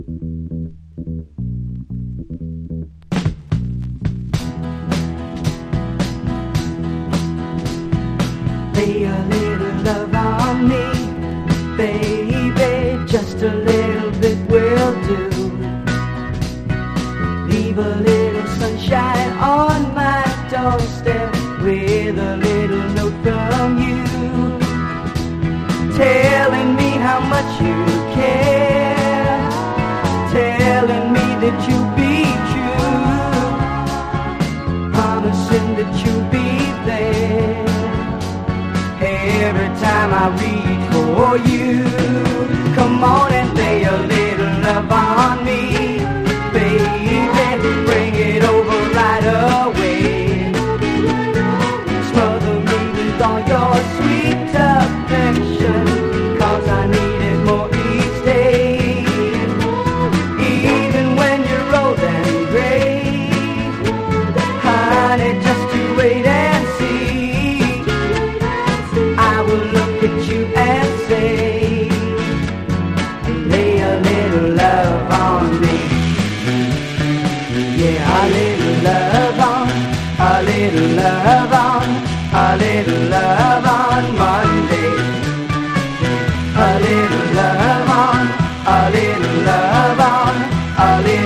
パパパ・コーラスが舞い上がるバブルガム・ソフトロック・クラシック！